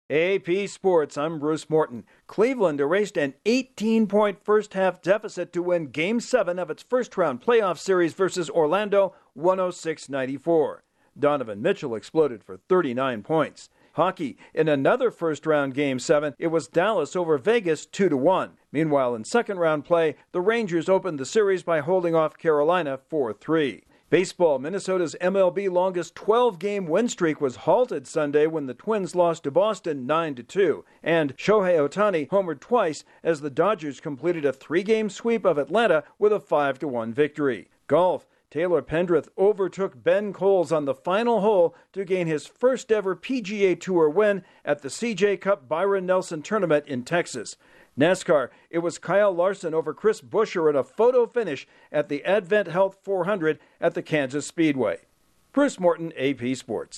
The NBA Cavaliers and NHL Stars each win a Game 7 to get out of the first round of the playoffs, The Twins' win streak comes to a halt and in a photo finish, Kyle Larsen wins the NASCAR event at Kansas Speedway. Correspondent